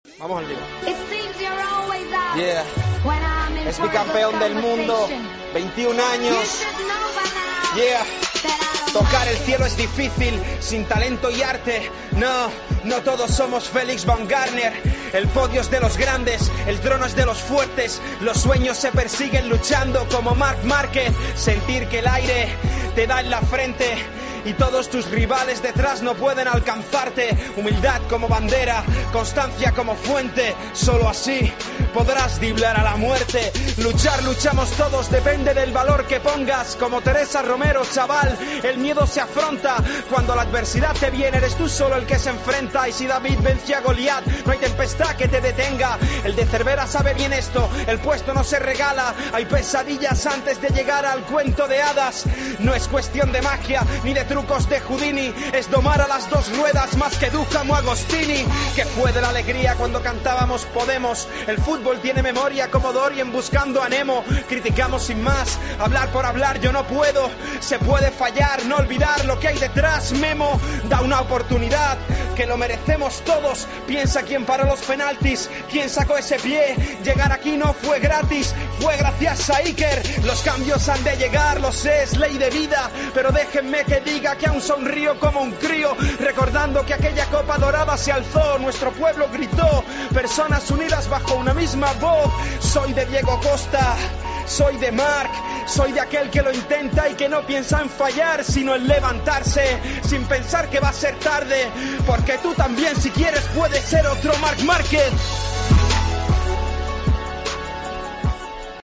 Cerramos Tiempo de Juego a ritmo de rap